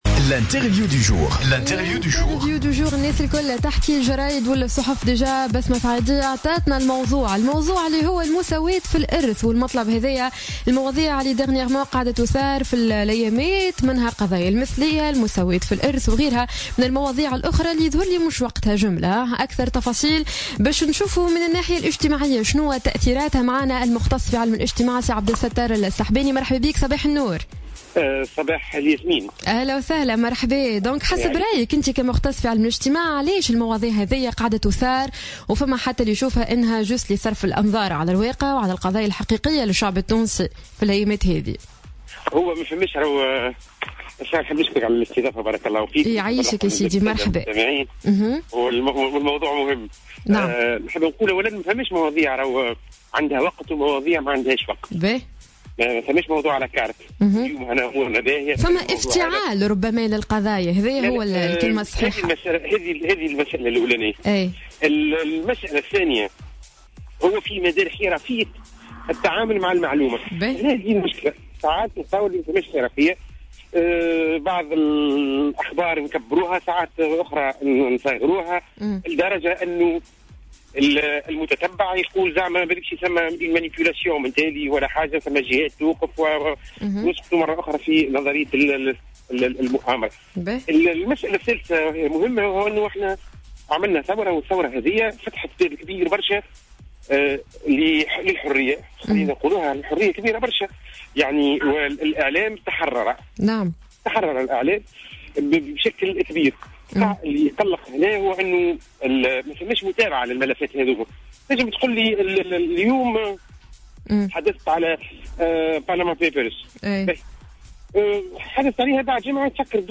الهدف من اثارة مسائل المساواة في الميراث والمثلية : مختص في علم الاجتماع يجيب